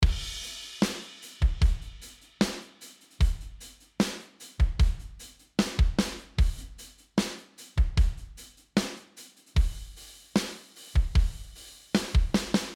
The same thing can be applied to the whole drum kit, too, to give you some extra juice and bigness.
Drums Kit With Parallel Compression On The Whole Kit
You might notice that the drums got a bit louder the more parallel compression got applied but focus on the length of the kick and snare drum.
Drums-PL-on-whole-kit.mp3